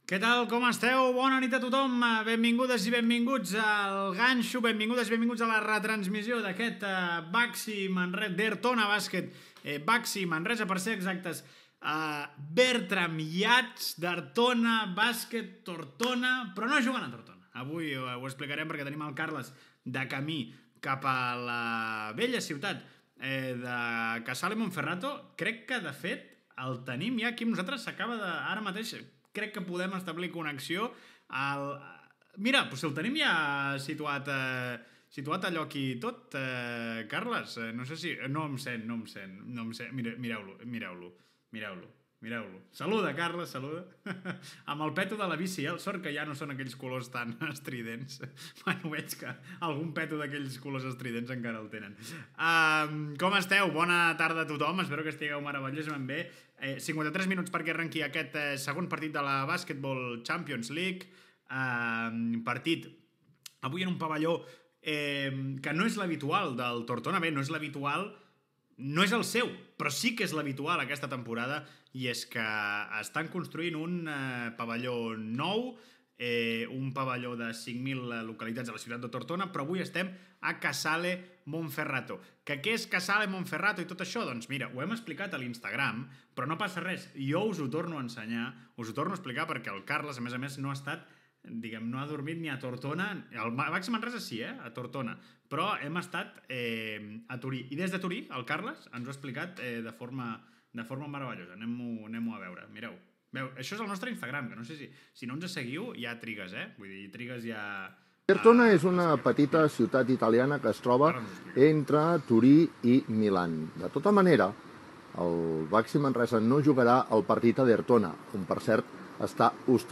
Gènere radiofònic Esportiu